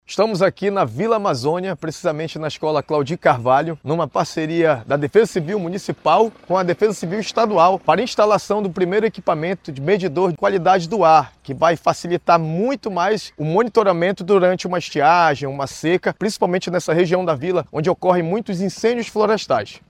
De acordo com o secretário municipal da Defesa Civil, Adriano Aguiar, a instalação do medidor representa um avanço importante para o município.